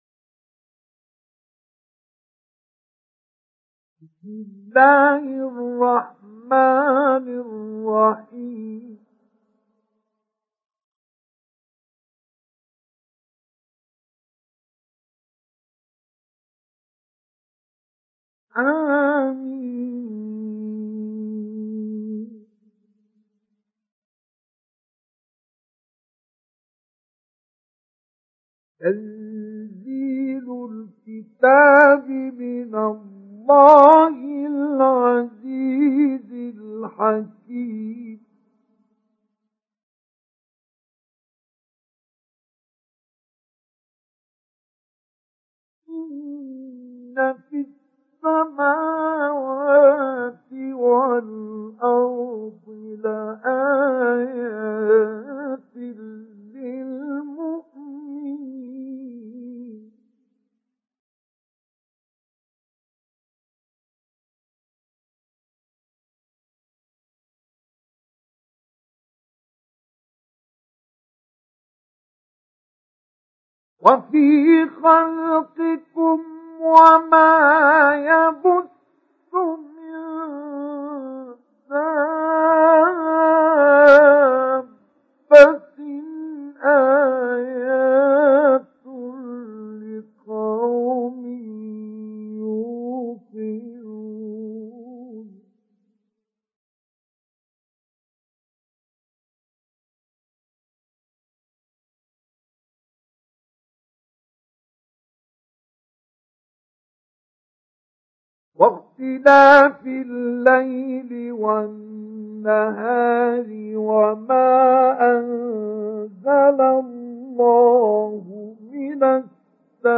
سُورَةُ الجَاثِيَةِ بصوت الشيخ مصطفى اسماعيل